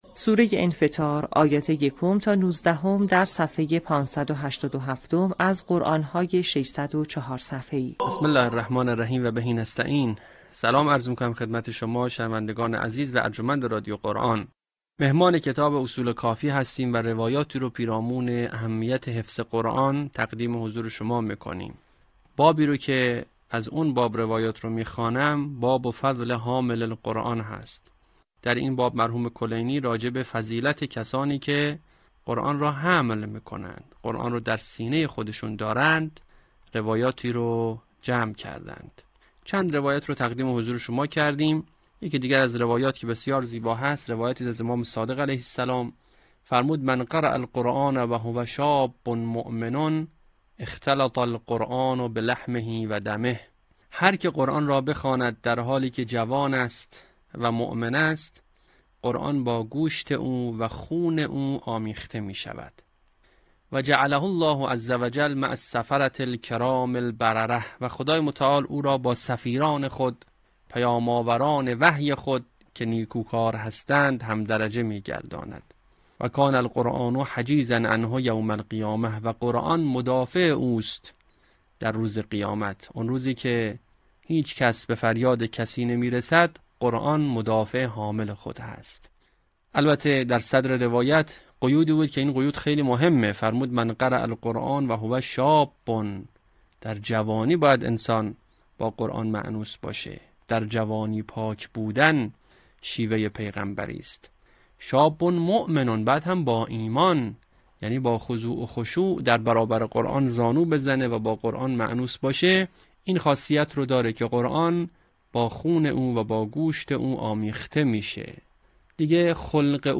آموزش حفظ جزء 30 آیات 1 تا 19 سوره انفطار